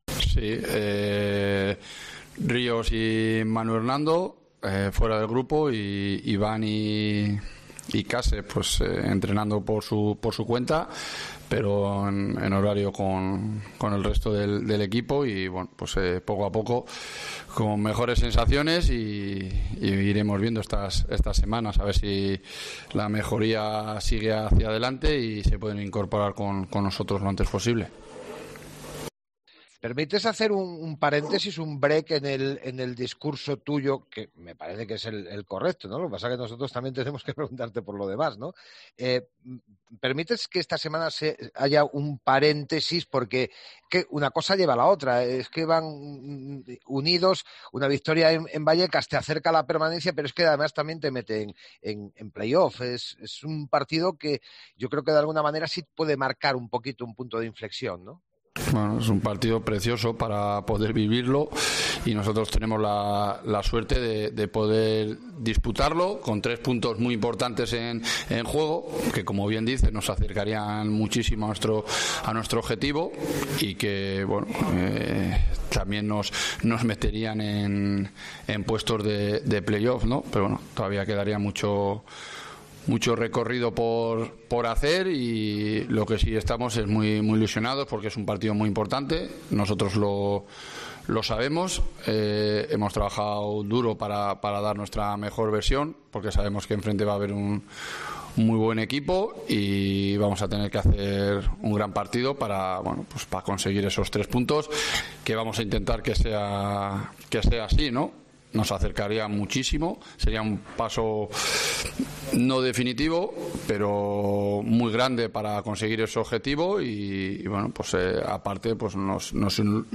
AUDIO: Escucha aquí las palabras del entrenador de la Ponferradina